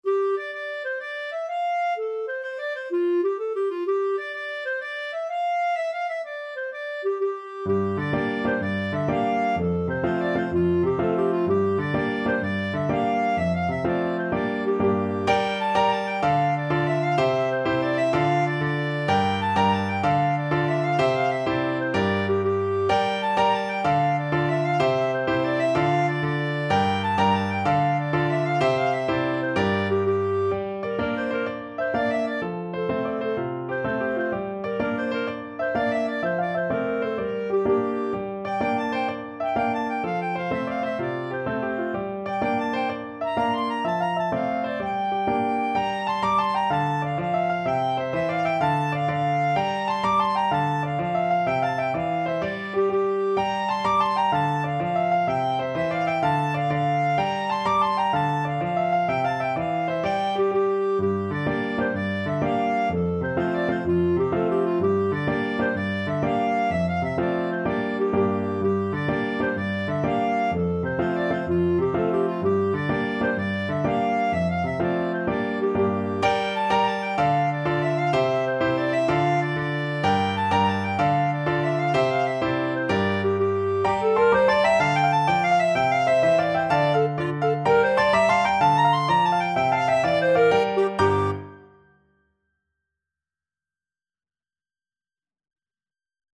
Clarinet
D minor (Sounding Pitch) E minor (Clarinet in Bb) (View more D minor Music for Clarinet )
Fast .=c.126
12/8 (View more 12/8 Music)
Irish